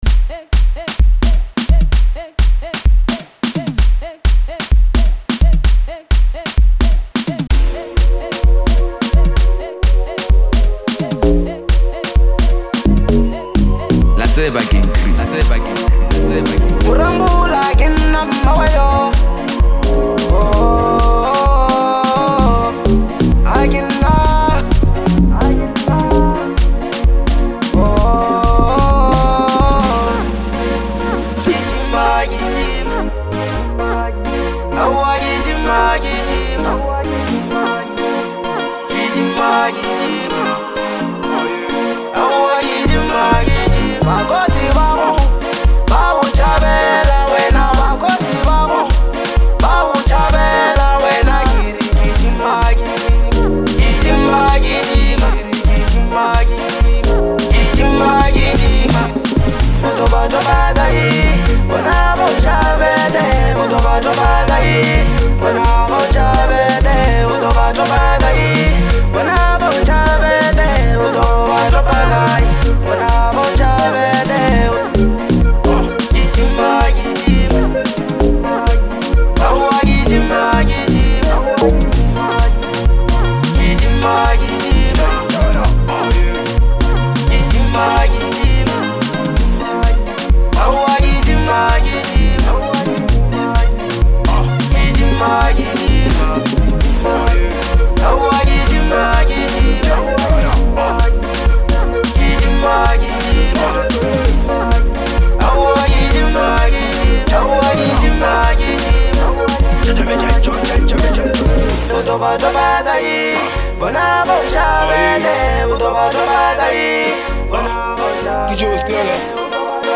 In fact , this song is in the genre of bolo house.